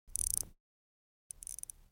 دانلود آهنگ ساعت 19 از افکت صوتی اشیاء
جلوه های صوتی
دانلود صدای ساعت 19 از ساعد نیوز با لینک مستقیم و کیفیت بالا